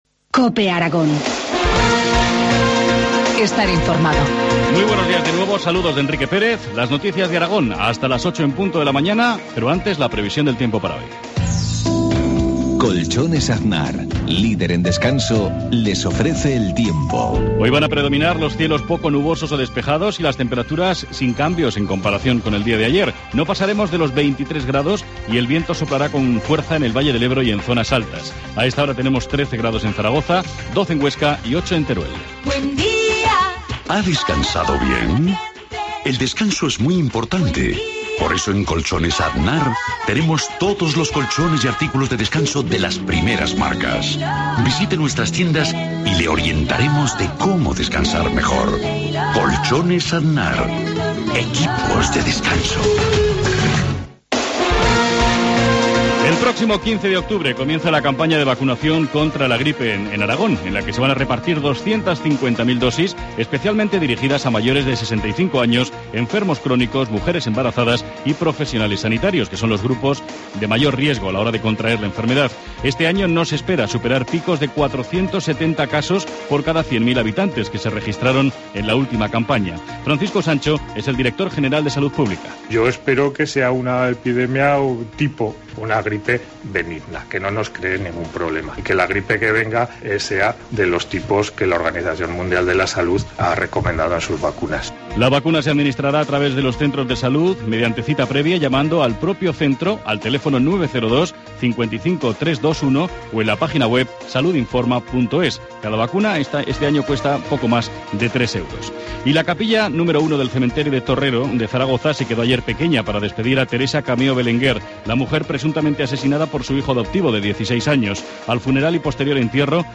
Informativo matinal, jueves 9 de octubre, 7.53 horas